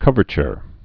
(kŭvər-chər, -chr)